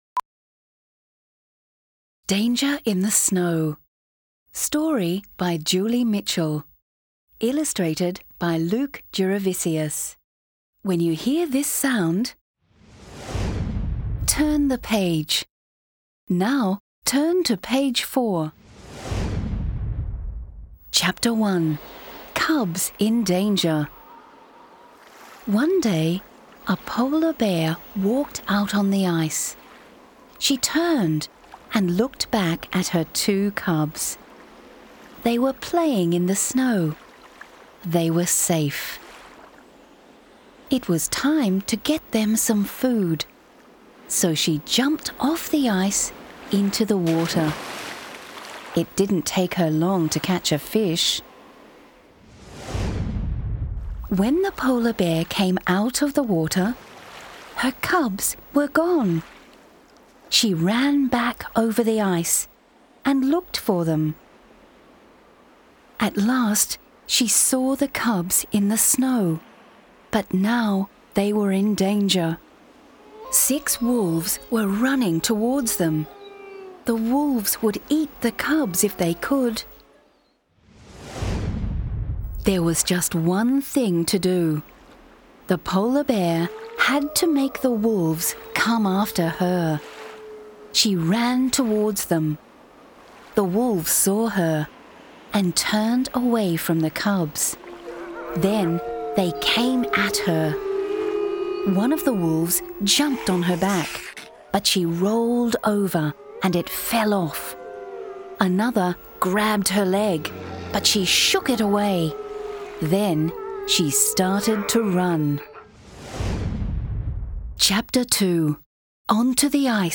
Type : Short Story